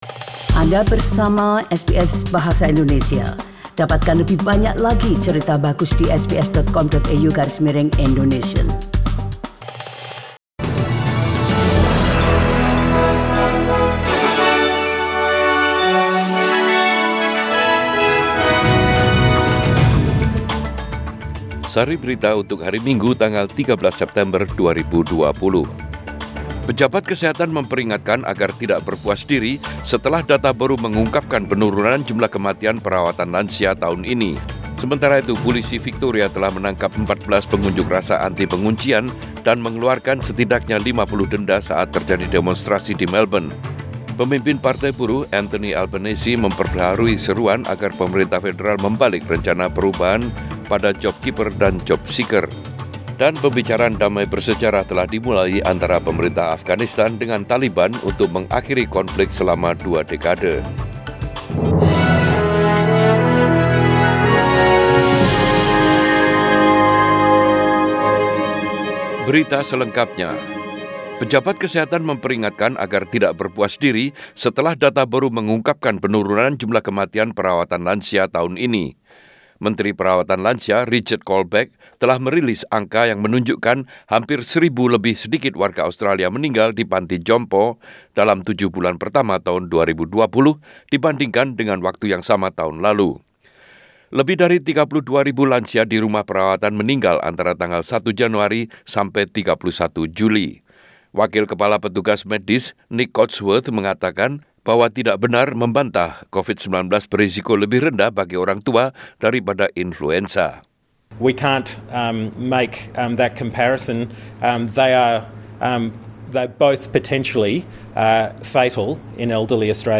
Warta Berita Radio SBS Program Bahasa Indonesia Source: SBS